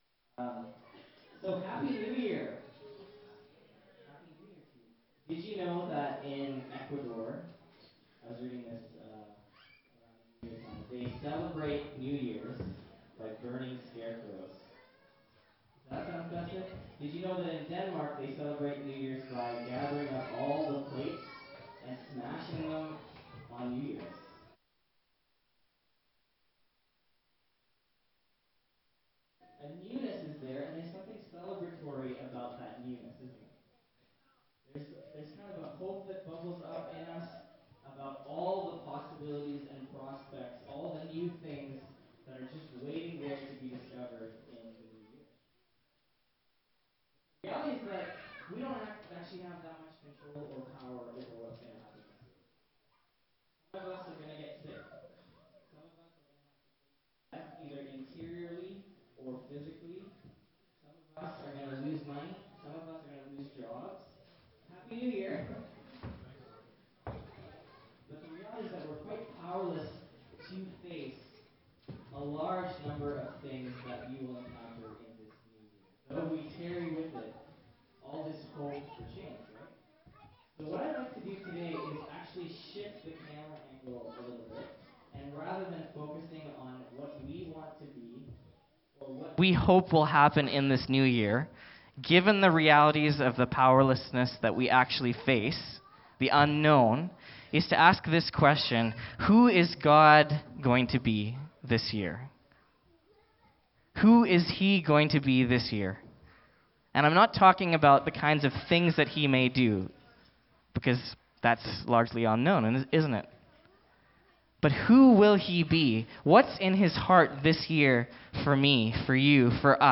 Passage: Mark 1:4-11 Service Type: Downstairs Gathering